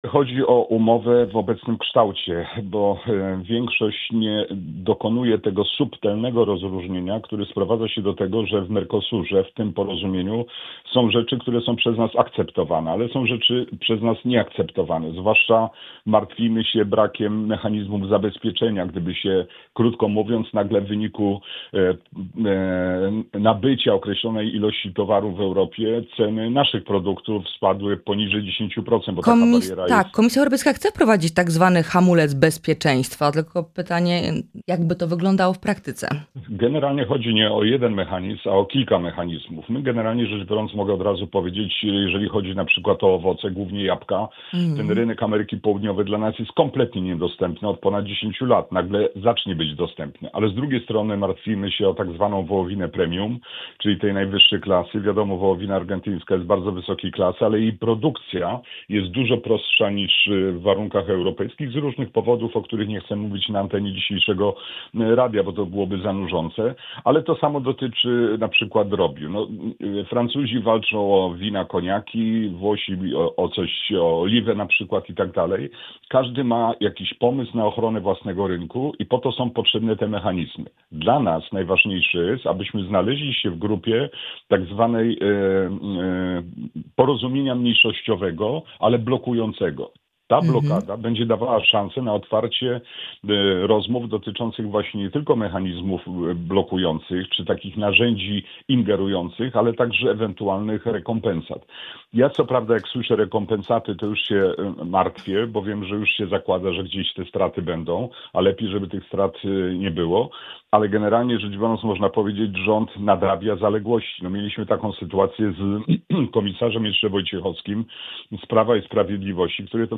W rozmowie „Poranny Gość” z europosłem Bogdanem Zdrojewskim rozmawialiśmy o wizycie prezydenta Karola Nawrockiego w USA, relacjach z rządem, a także o zmianach i wyborach w PO.